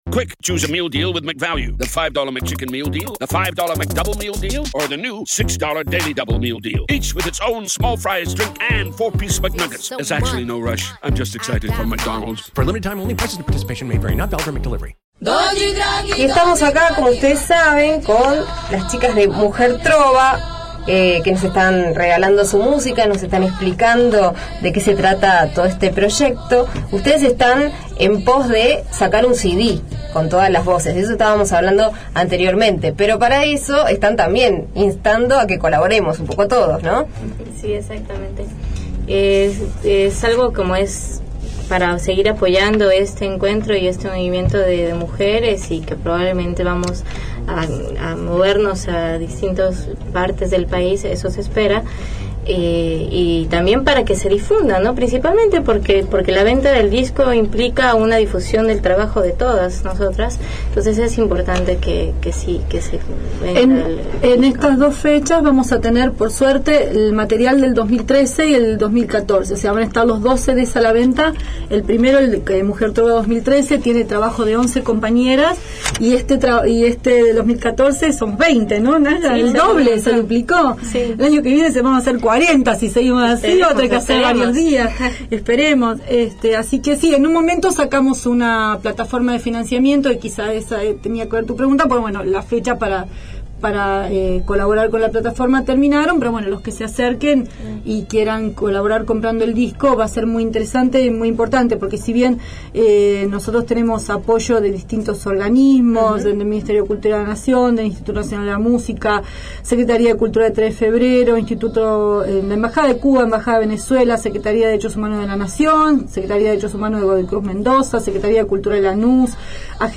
Entrevista Mujer Trova | Parte 2